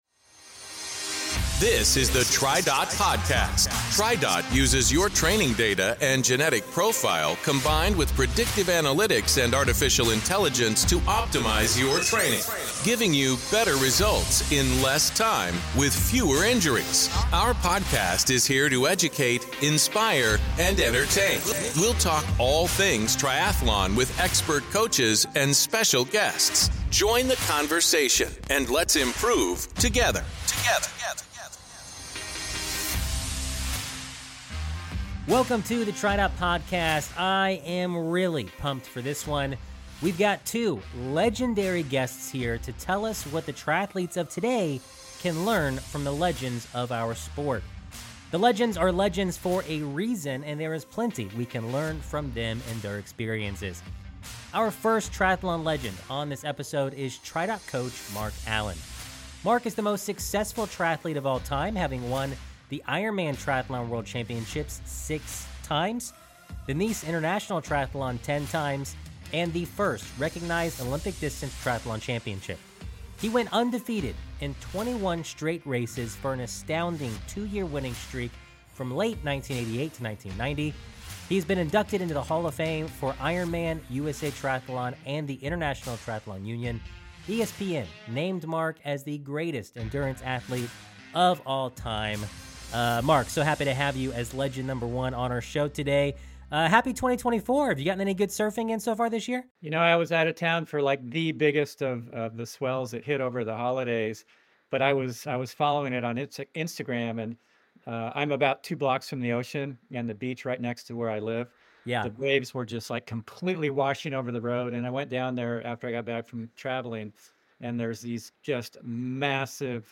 In this special episode, we are joined by two of the most iconic figures in the sport of triathlon - Mark Allen and Kathleen McCartney.